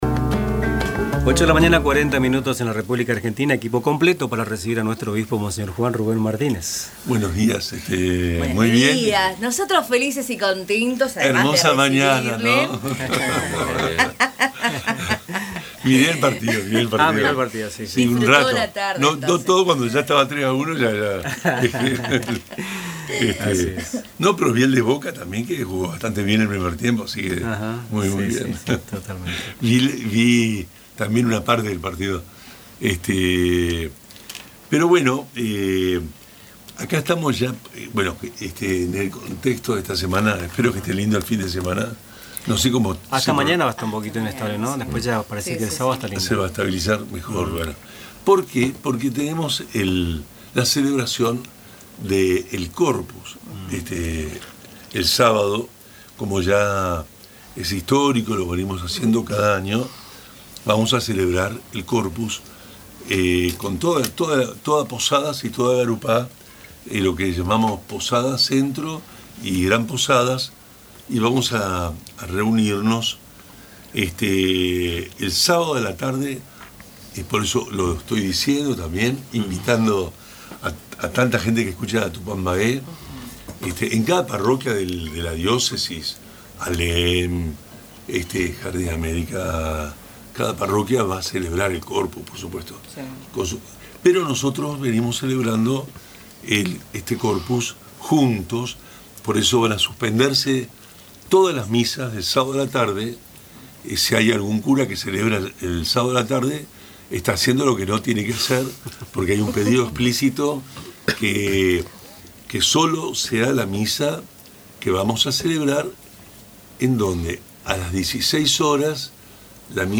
En su tradicional espacio de los miércoles en Radio Tupambaé, monseñor Juan Rubén Martínez advirtió sobre las graves consecuencias sociales de la desatención estatal, y reflexionó sobre la actualidad pastoral y regional. Habló de la crisis de los yerbateros, convocó al Corpus Christi diocesano y pidió rezar por la paz en Medio Oriente.